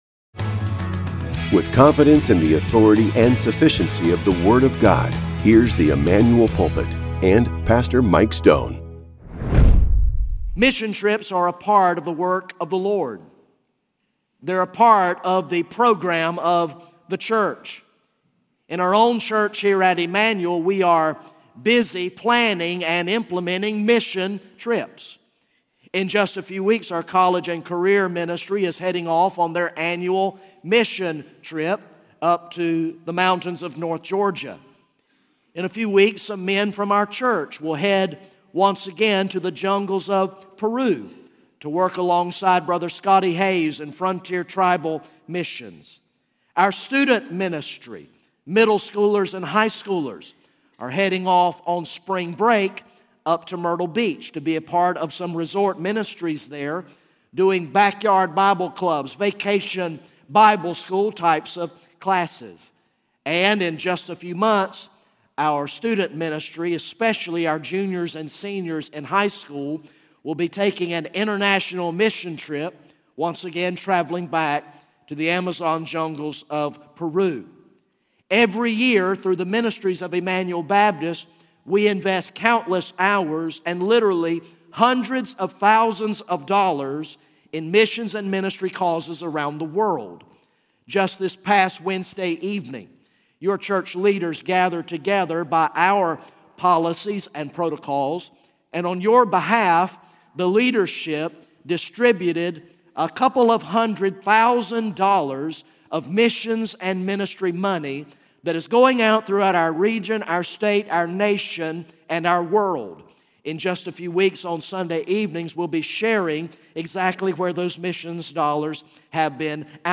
GA Message #11 from the sermon series entitled “King of Kings